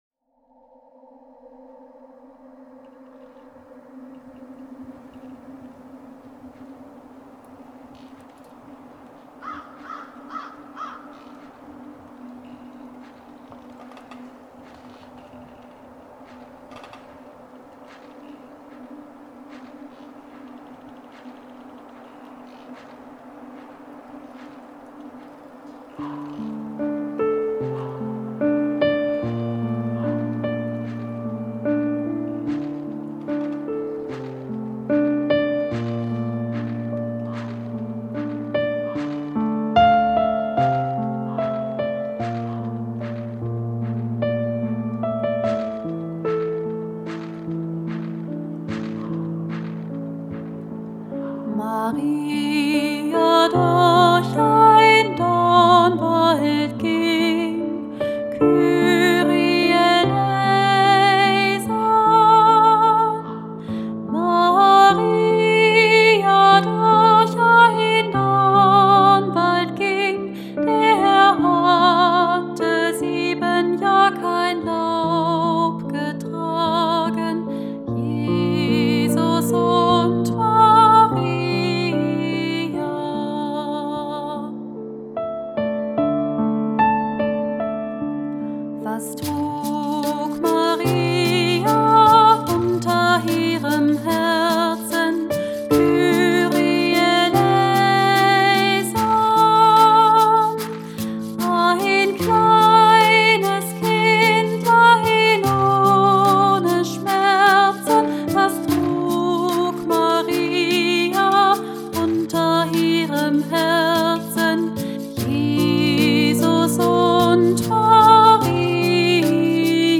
diese alte weihnachtliche Melodie gehört
Der Anfang ist ein alter Choral-Satz und